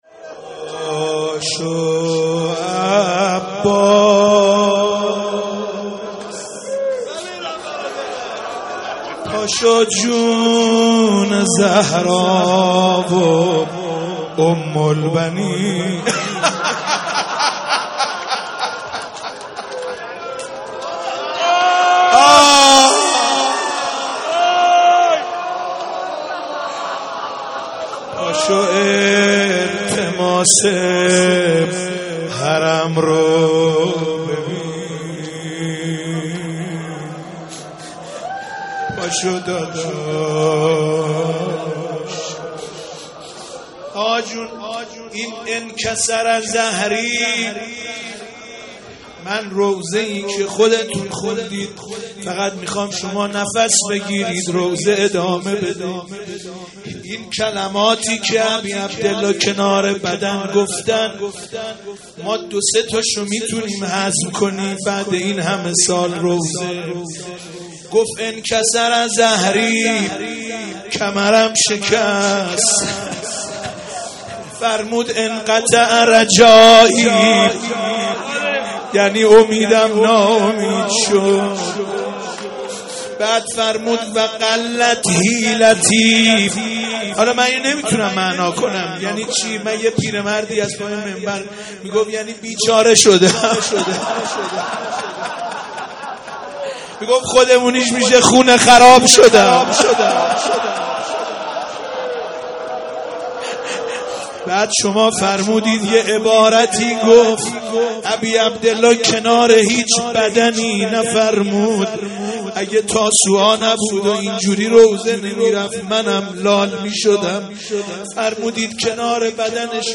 مداحی روز نهم محرم 1402
مداحی و سینه زنی
روز تاسوعا 1445